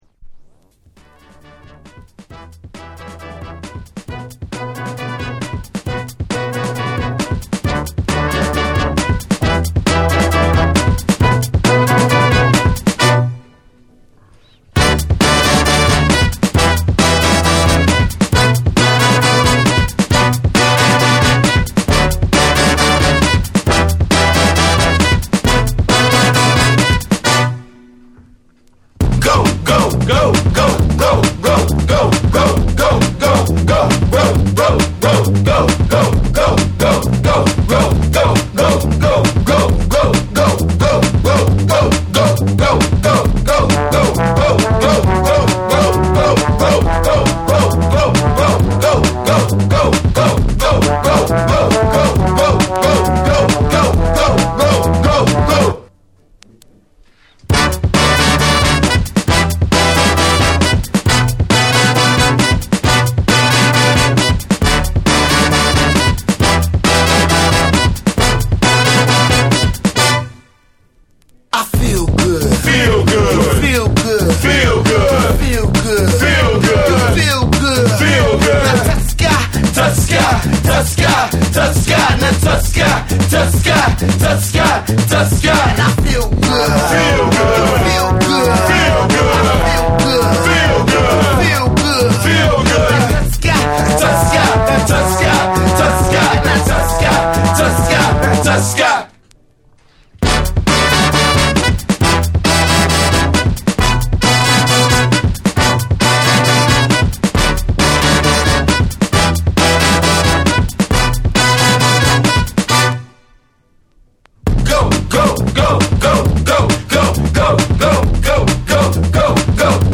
SOUL & FUNK & JAZZ & etc / BREAKBEATS